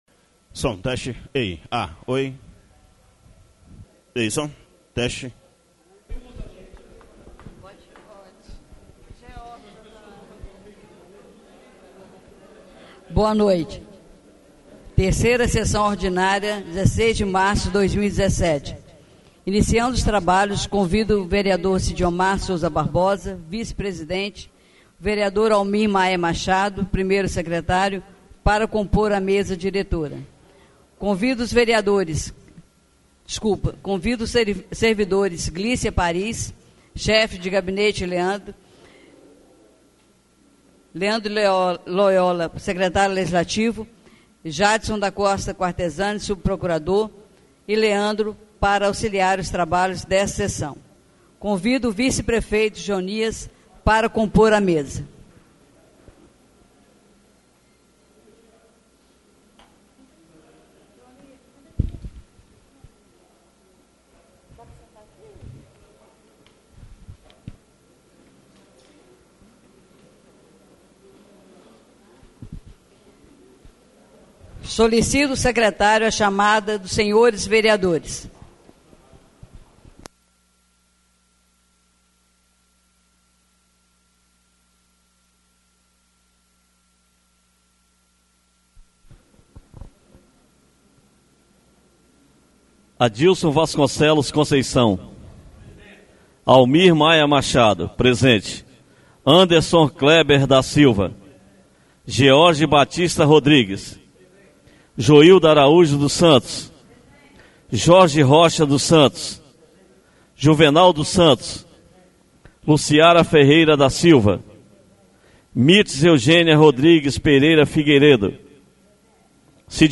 3ª (TERCEIRA) SESSÃO ORDINÁRIA DO DIA 16 DE MARÇO DE 2017 BRAÇO DO RIO